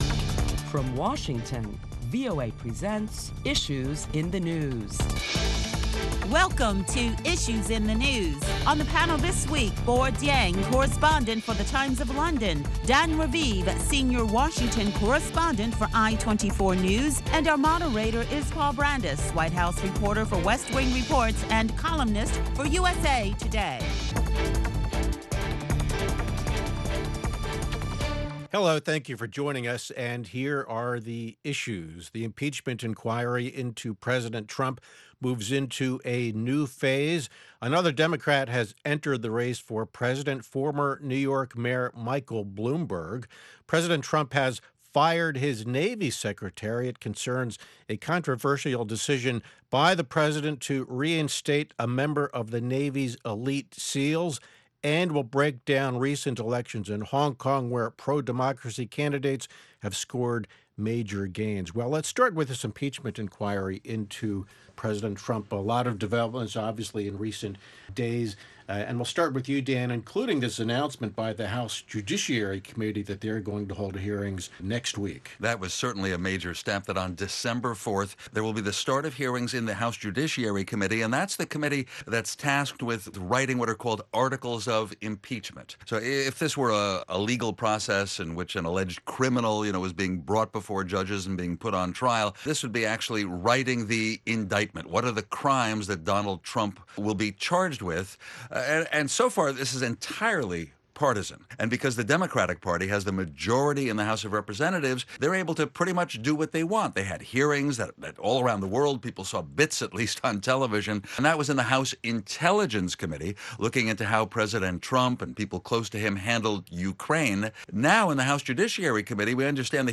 Listen to a panel of prominent Washington journalists as they deliberate the latest top stories that include how Democrats are responding to former New York City mayor and billionaire Michael Bloomberg announcing his candidacy for president of the United States